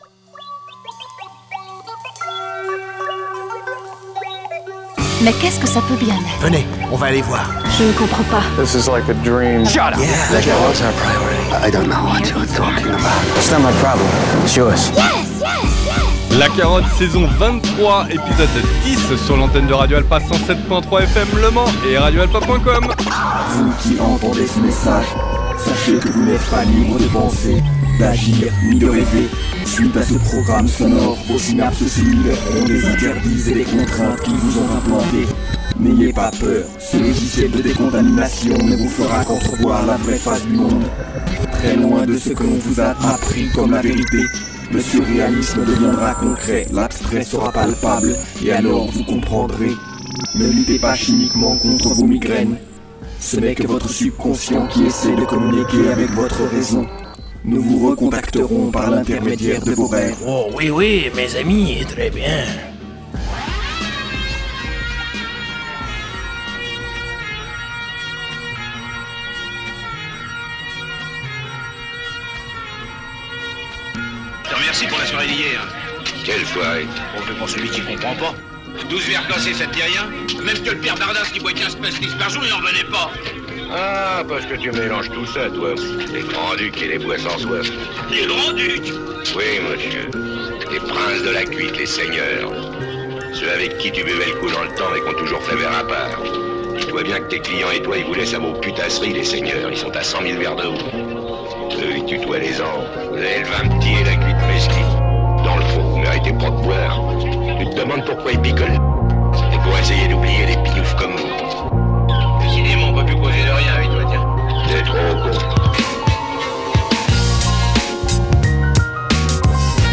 Nouveau voyage dans un maelstrom instrumental qui tourbillonne pour relâcher pléthores de propositions où la musique est au centre.